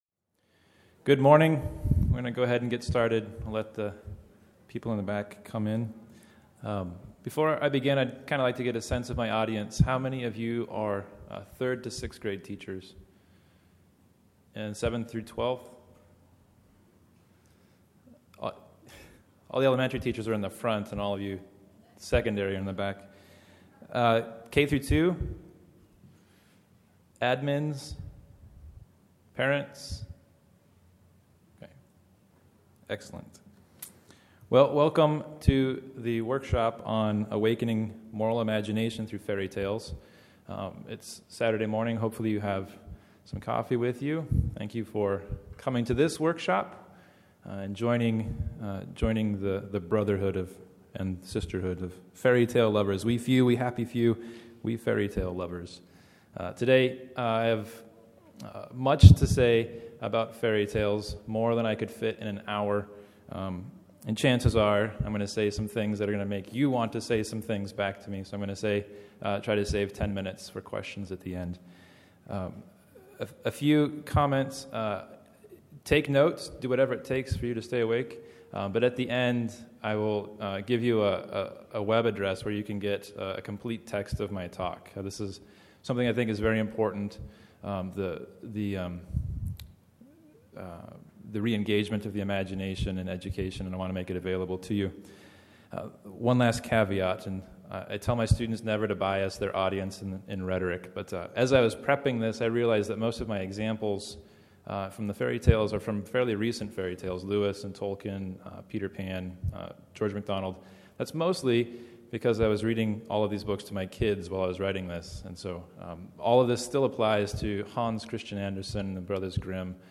2012 Workshop Talk | 0:59:25 | All Grade Levels, Virtue, Character, Discipline
This workshop discusses the philosophy and techniques for using fairy tales to awaken the moral imagination. Speaker Additional Materials The Association of Classical & Christian Schools presents Repairing the Ruins, the ACCS annual conference, copyright ACCS.